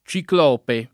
©ikl0pe] s. m. (mit.) — antiq. ciclopo [©ikl0po]: Benché possente più d’ogni Ciclopo [